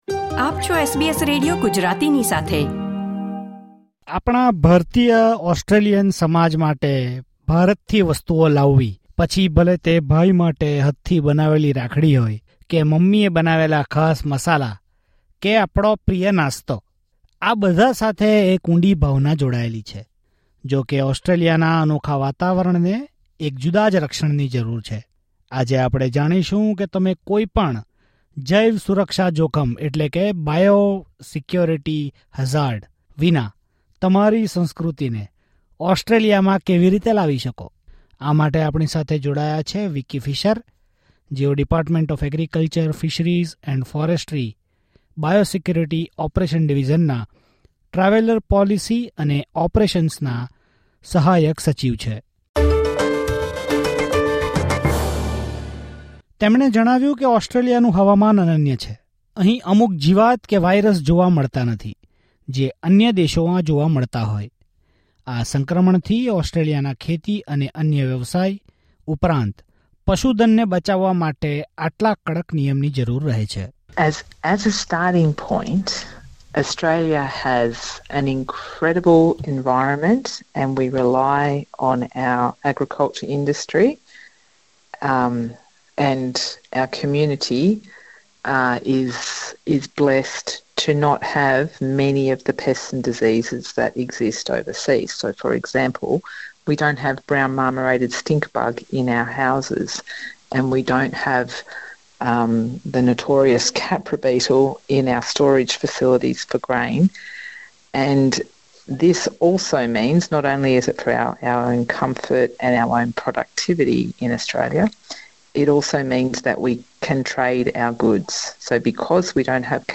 Australian biosecurity and the hefty fines given for it create a panic among many people. In this report, find out how you can avoid this fine.